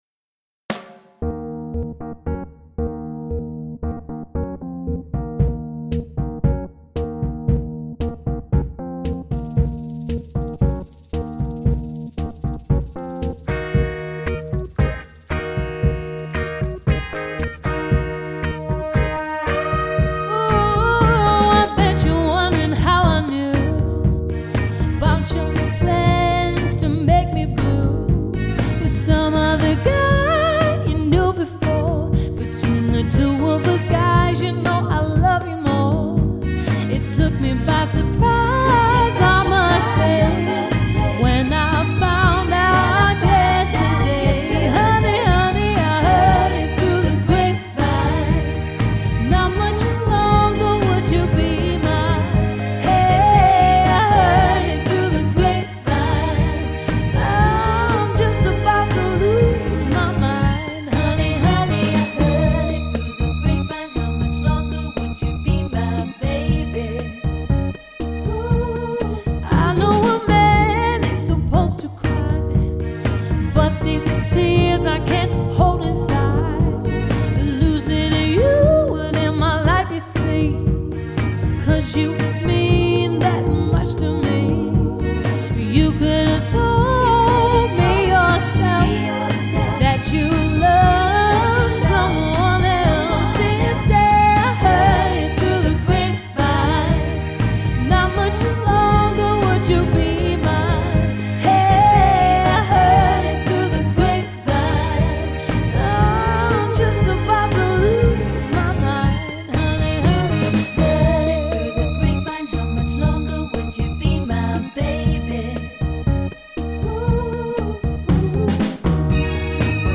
Live Lounge Solo/ Duo Set Available